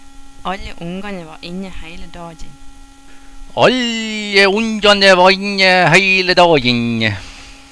Da fyste so ryke når enj reise vekk, e dissa lydanje her: ”nj” – lyden